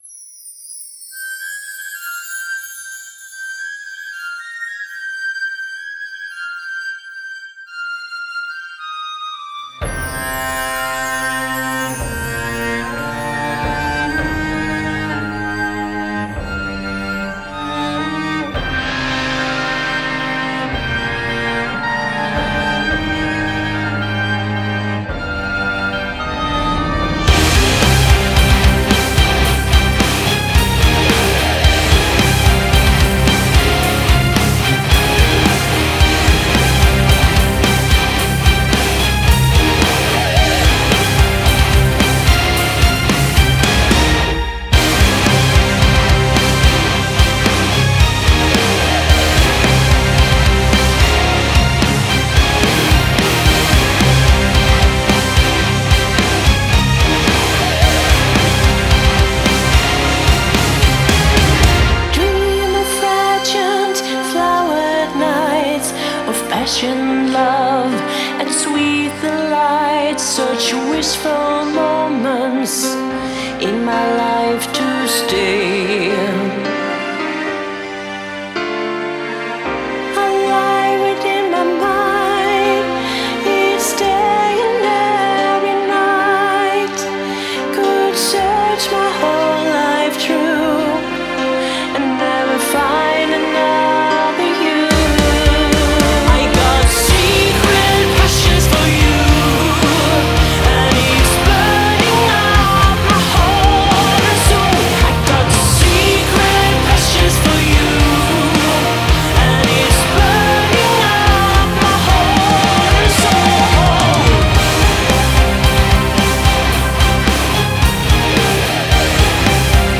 Симфо-метал, готик-метал